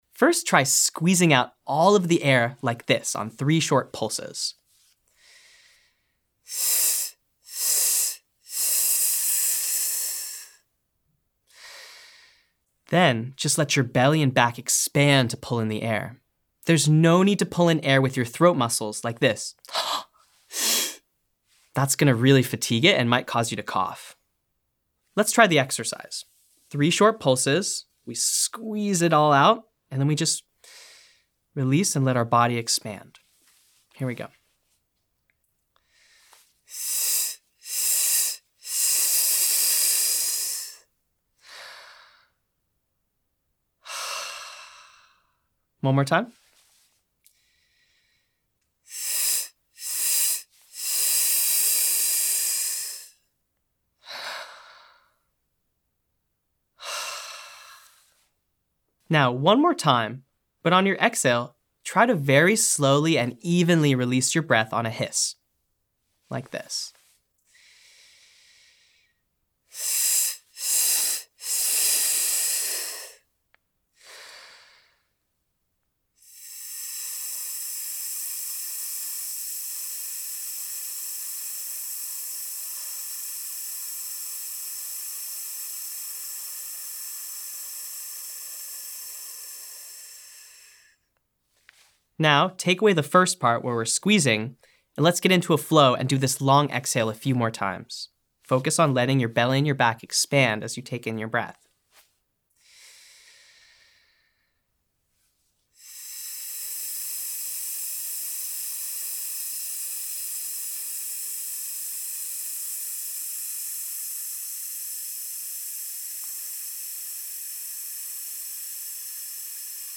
Exercise 1: Breath Control and Sustain